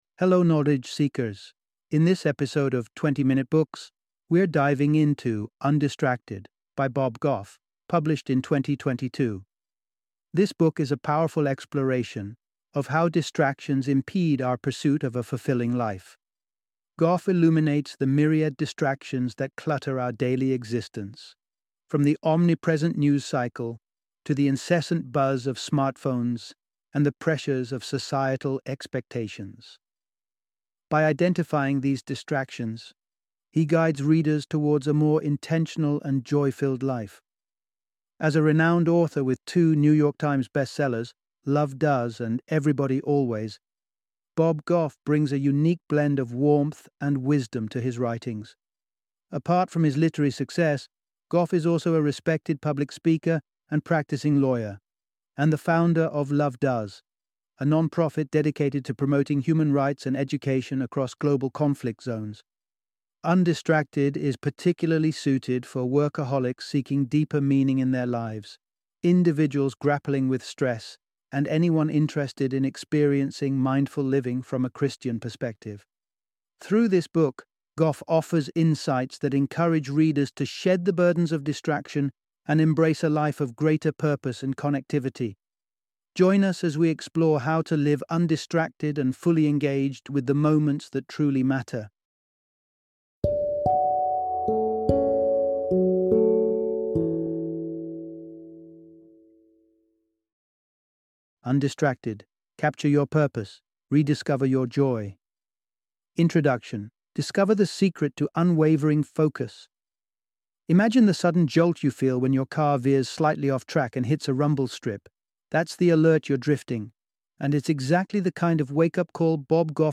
Undistracted - Audiobook Summary